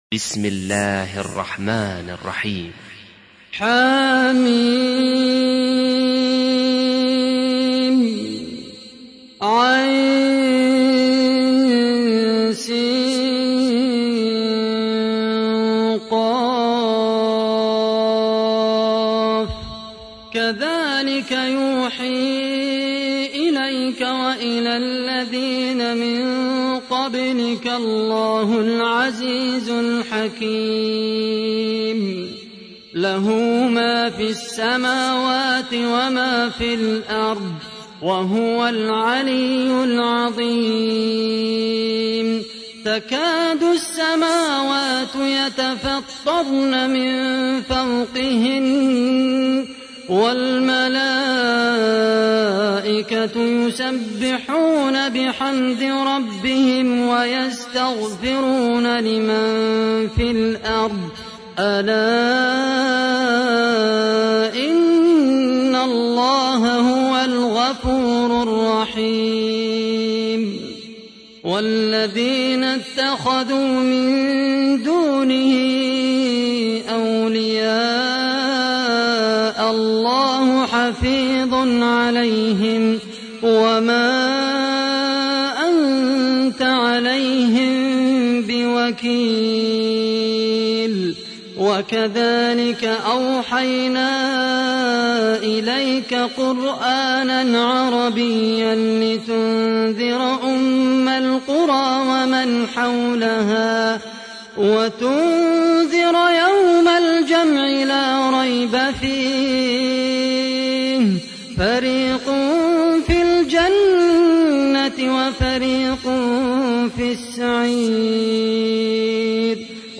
تحميل : 42. سورة الشورى / القارئ خالد القحطاني / القرآن الكريم / موقع يا حسين